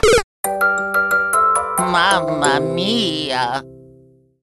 A Luigi variant of an arrangement